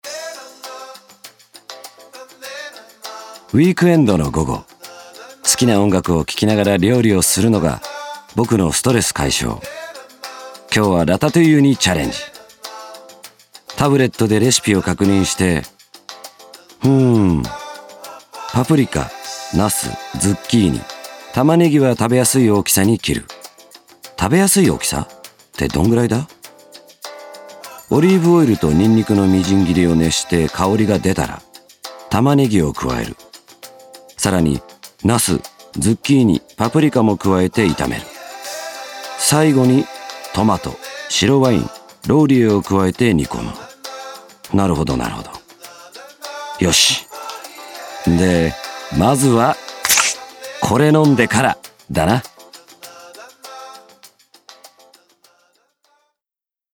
パワー溢れるボイスから、繊細なタッチまで幅広くこなす。